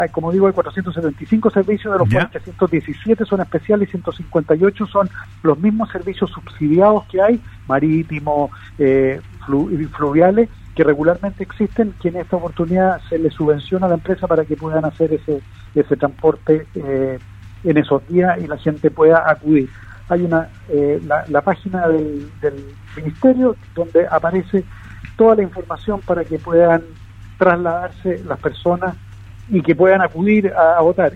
Es por eso que Geisse, a la salida de su votación en la comuna de Río Negro durante esta mañana, invitó a las personas de la región a asistir a los locales de votación, a elegir a su próximo gobernador, ya que, afirmó, es una oportunidad para democratizar las decisiones que se tomen a nivel regional.
El intendente precisó, en entrevista con Radio Sago, que existen más de 400 servicios de transporte gratuito para los habitantes de la región y así movilizarse hasta sus locales de votación.